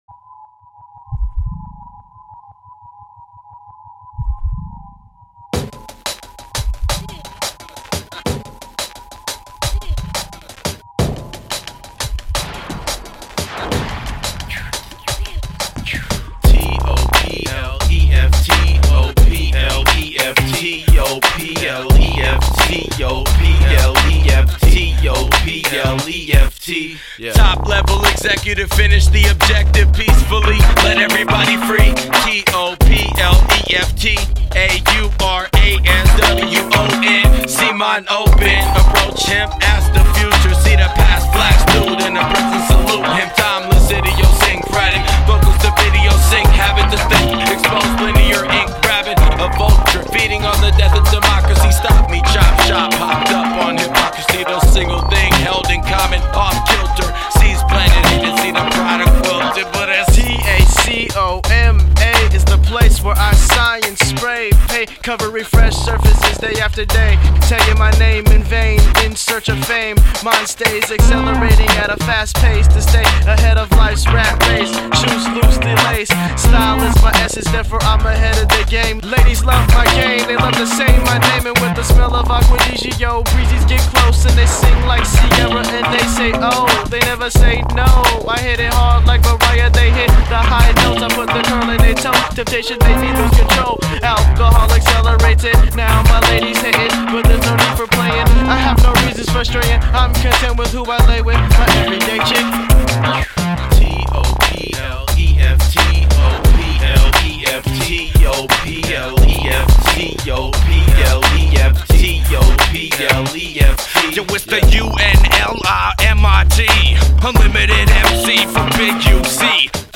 hip hop collective